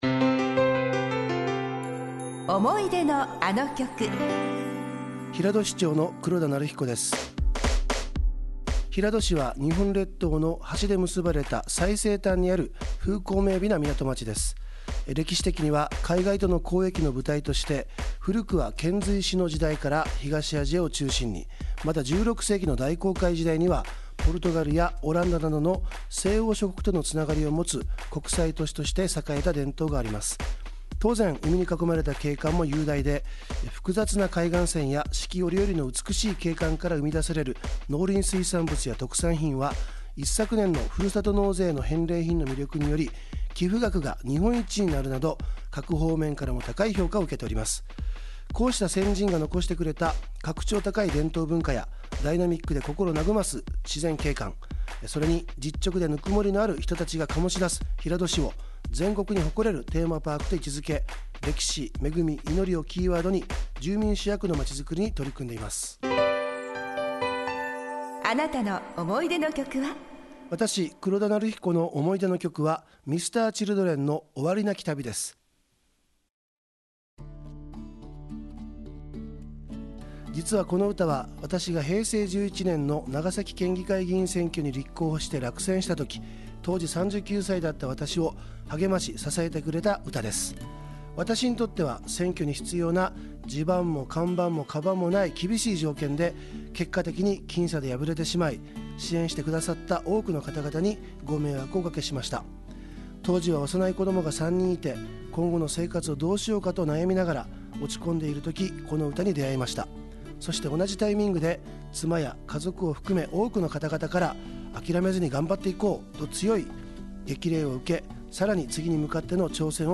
この曲の励ましで今の自分がある。そう熱く語って下さいます。